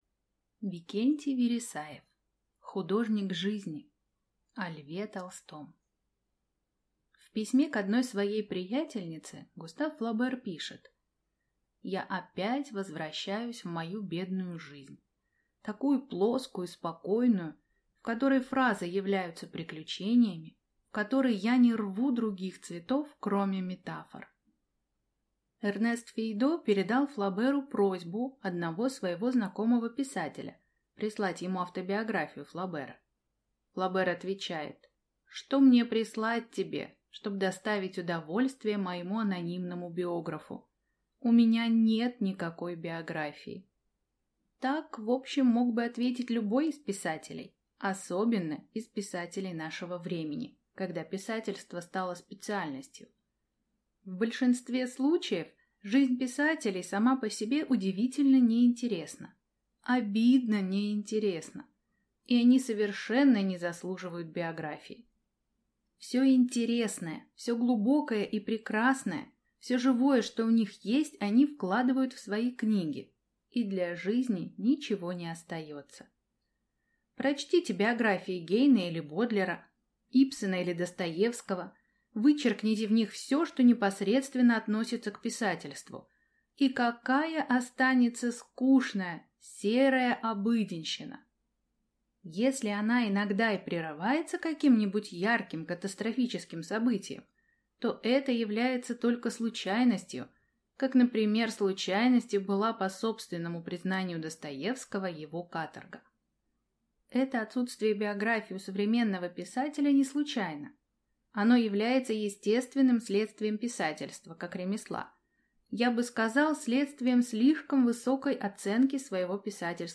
Аудиокнига Художник жизни | Библиотека аудиокниг